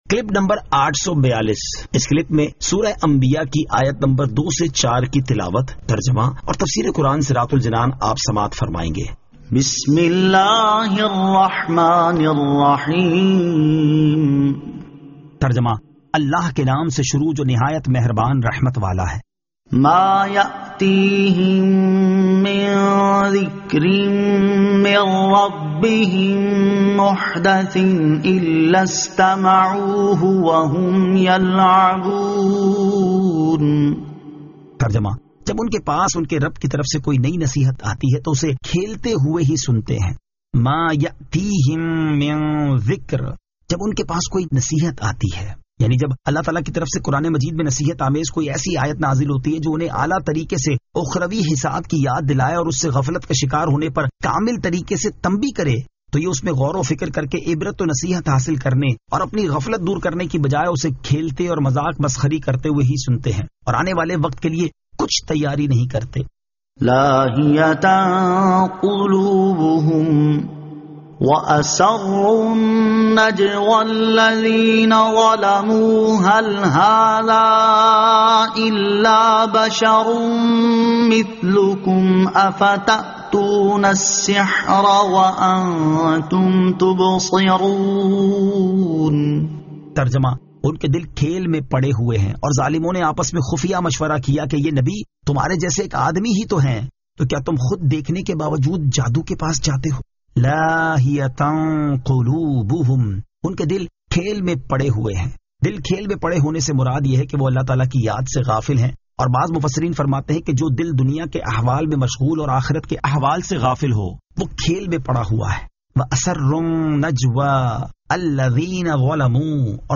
Surah Al-Anbiya 02 To 04 Tilawat , Tarjama , Tafseer